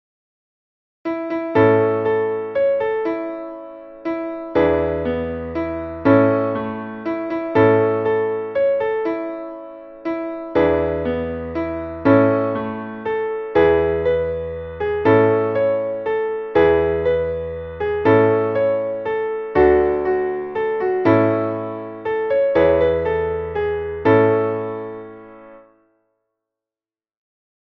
Traditionelles Volkslied